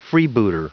Prononciation du mot freebooter en anglais (fichier audio)
Prononciation du mot : freebooter